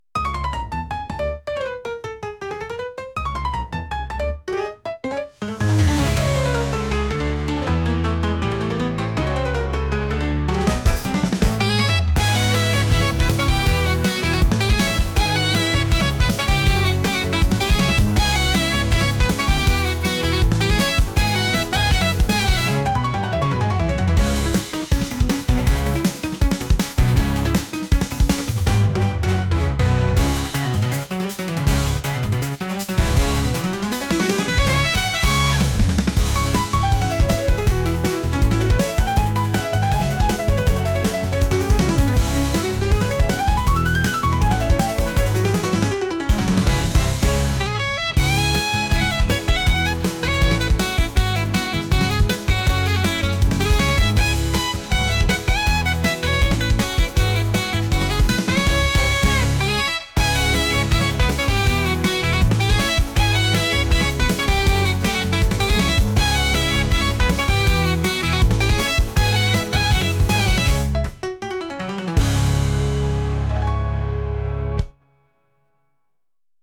能力バトルもので能力又は必殺技を繰り出した時のような音楽です。